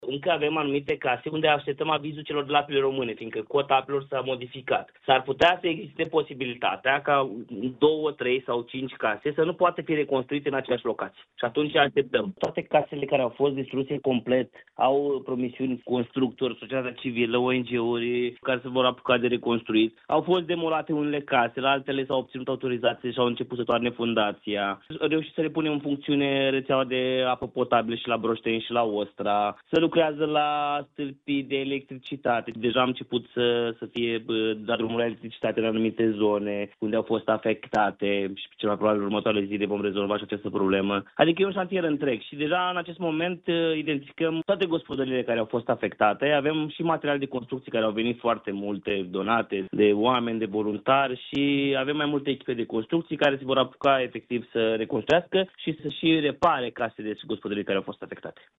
Gheorghe Șoldan a transmis pentru Radio Iași că așteaptă în continuare sprijin de la Guvern, pentru restabilirea infrastructurii.